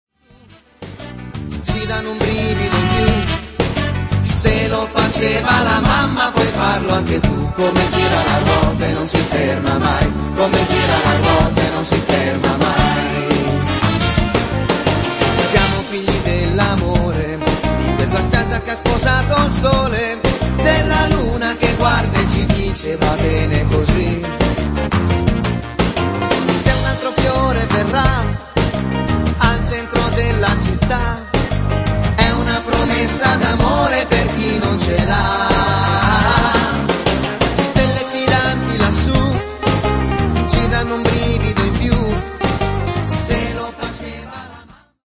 menehito